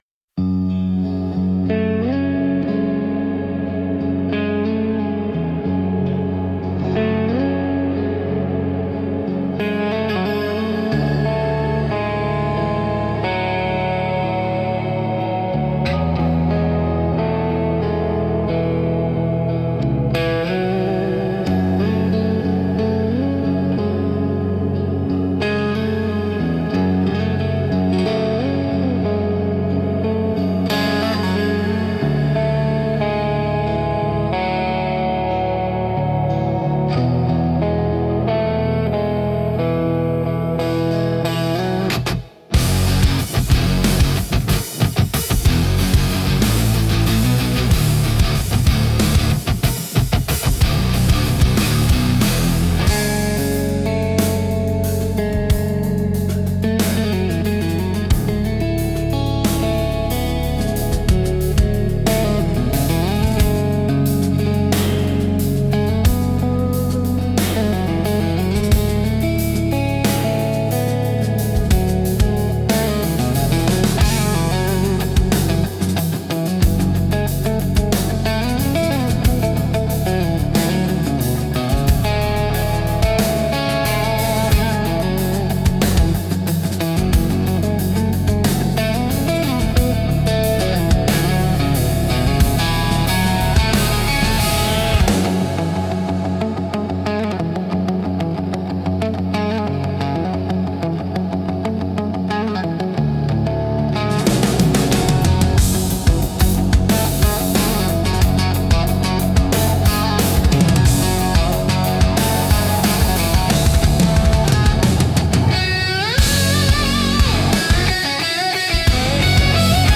Instrumental - West of the Last Exit 4.53